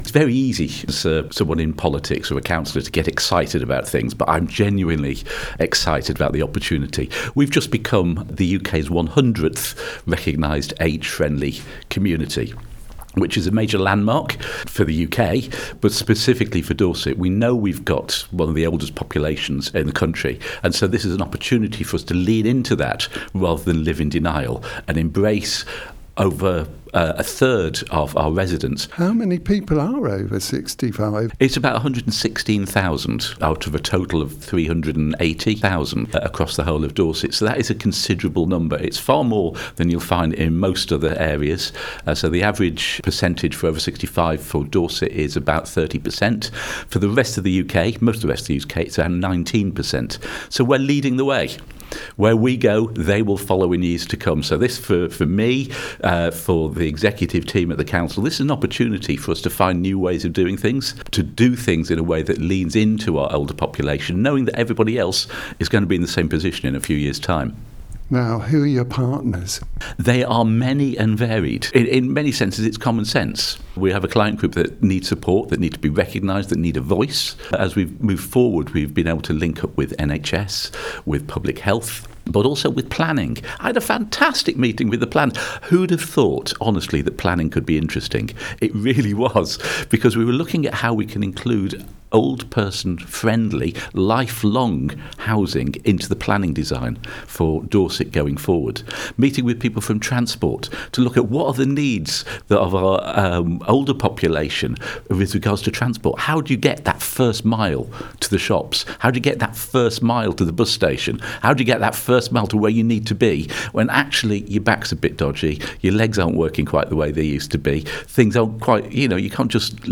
Steve called in to the studio and explained more…